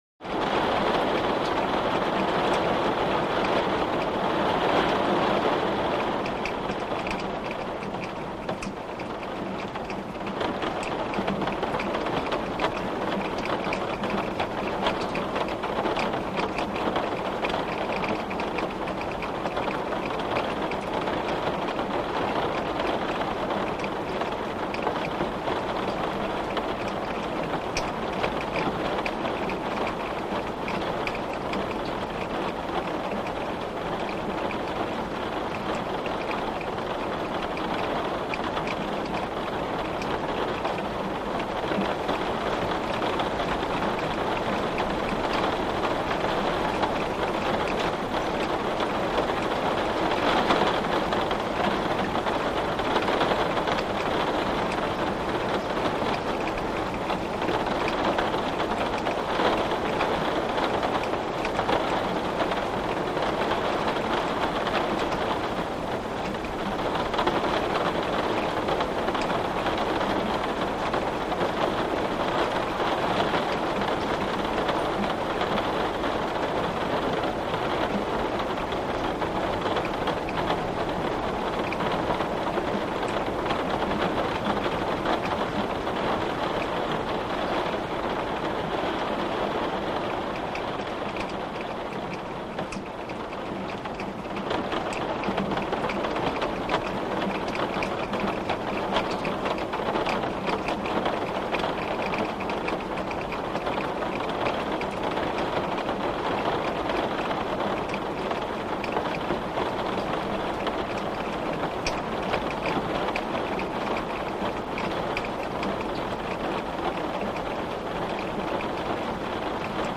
Glass Window | Sneak On The Lot
Rain; Heavy, On Glass Window In Roof.